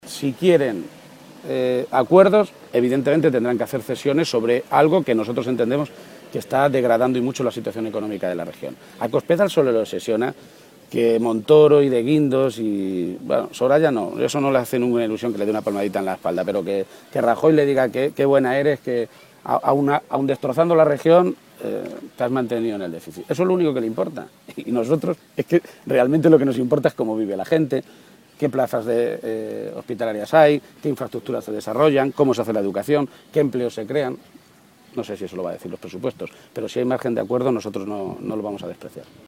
Se pronunciaba de esta manera García-Page esta mañana, en Toledo, a preguntas de los medios de comunicación, en relación a la reunión que mantendrán el próximo lunes el consejero de Hacienda del Gobierno regional, Arturo Romaní, y el portavoz del grupo socialista en las Cortes de Castilla-La Mancha, José Luis Martínez Guijarro.
Cortes de audio de la rueda de prensa